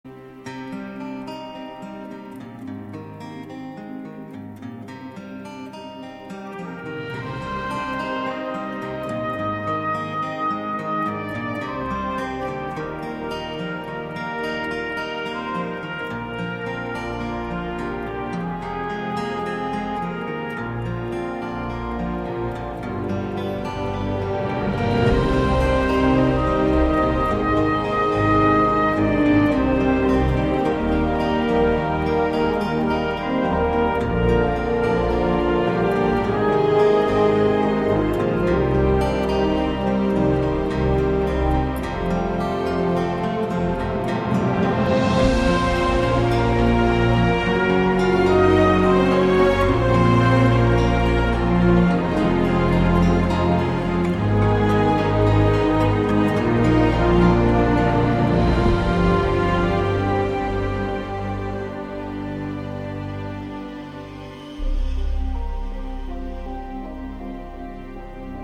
• Качество: 128, Stereo
гитара
саундтреки
без слов
красивая мелодия
инструментальные